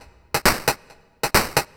DS 135-BPM C3.wav